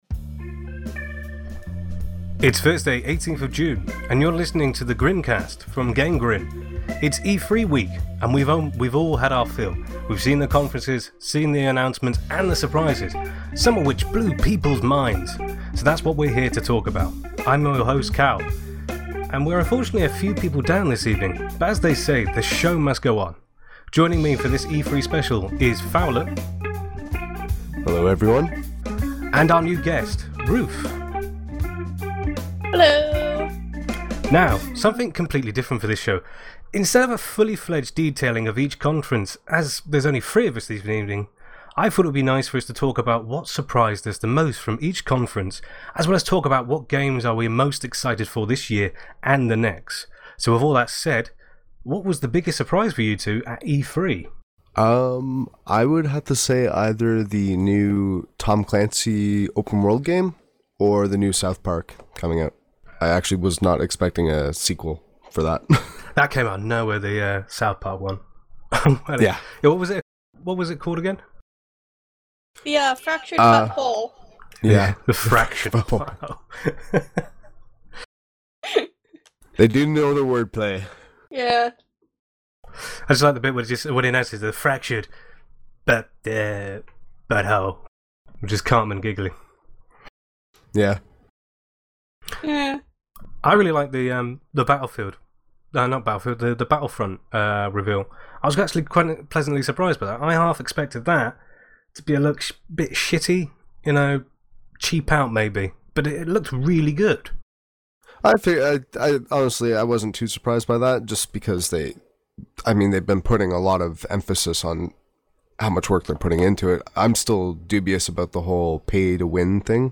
Instead of a fully fledged detailing of each conference (as there is only three of us on this weeks pod), we decided that it would be nice for us to talk about what surprised us the most from each conference and talk about what games are we are now most excited for this year and the next!